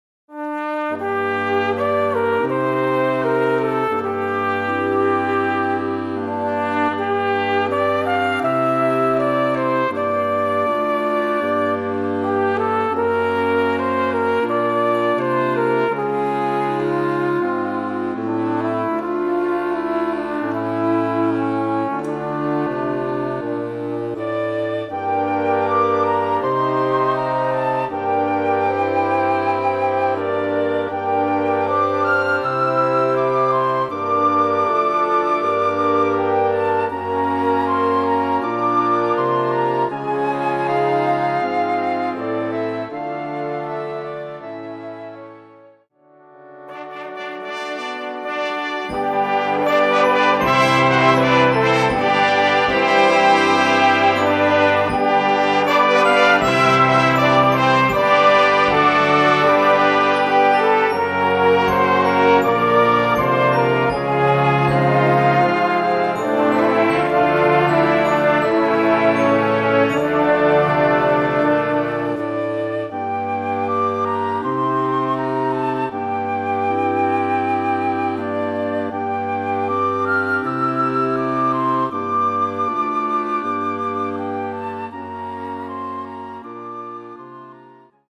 Kategorie Blasorchester/HaFaBra
Unterkategorie Choräle, Balladen, lyrische Musik
Besetzung Ha (Blasorchester)
Besetzungsart/Infos Ballade